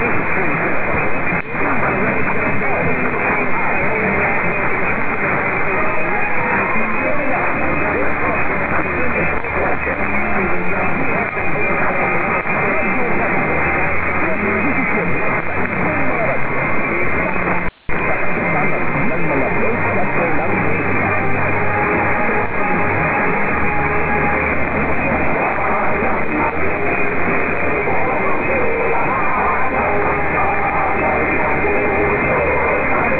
Instead, I took a quick re-listen to some 5 year old wav files from Grayland, WA and so far have found the following that I missed the first time - from Feb. 21, 2010 @ 1500utc on 1017kHz:
philippines-1017-radyo-rapido.wav